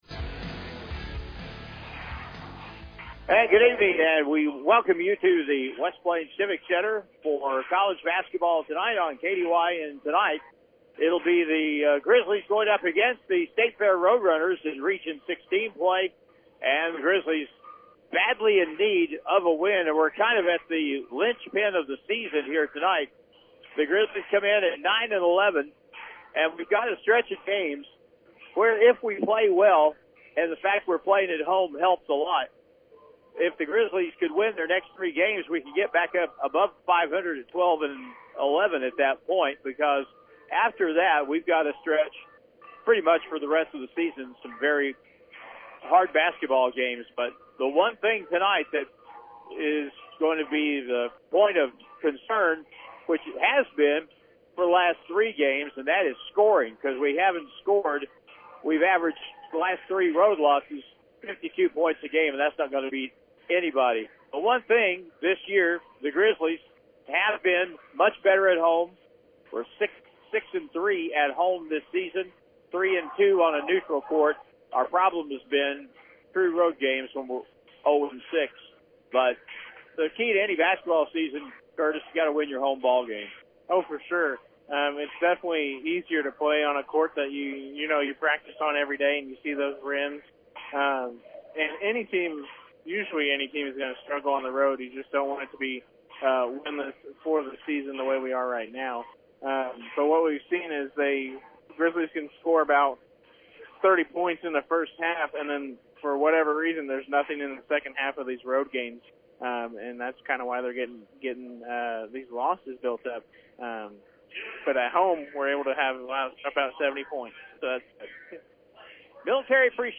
Missouri-State-West-Plains-Grizzlies-vs.-State-Fair-Roadrunners-1-25-25.mp3